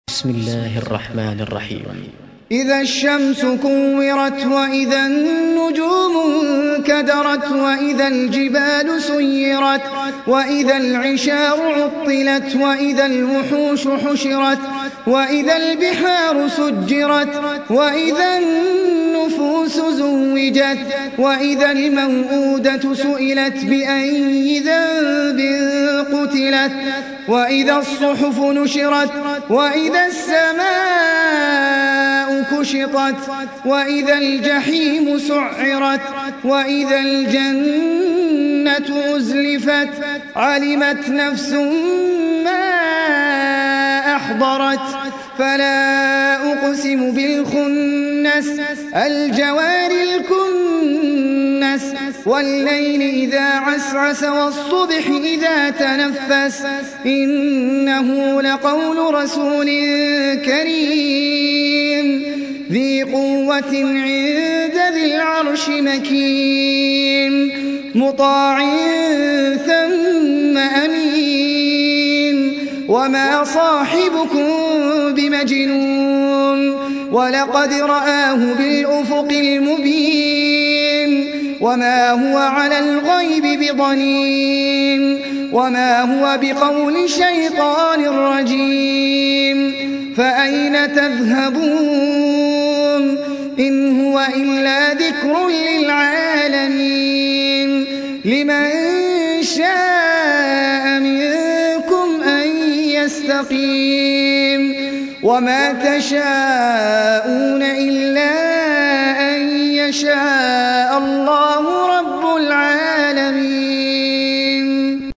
سورة التكوير | القارئ أحمد العجمي